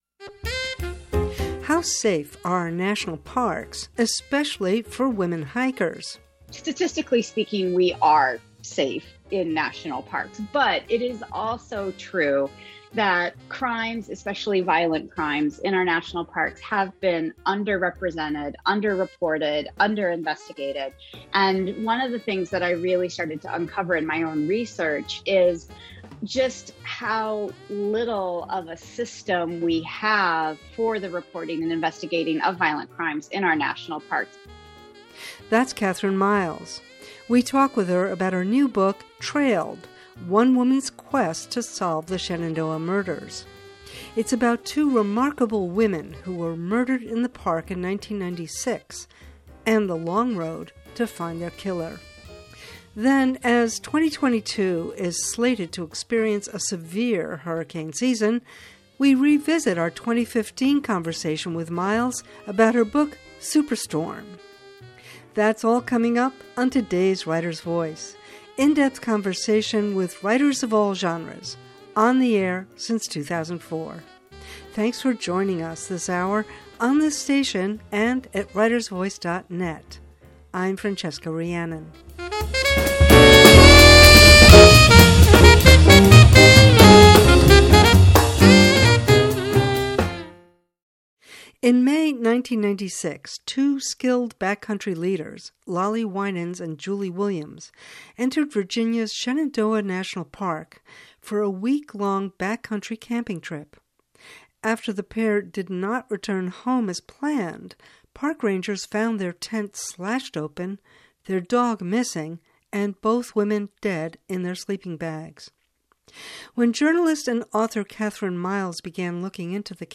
Writer’s Voice — in depth conversation with writers of all genres, on the air since 2004.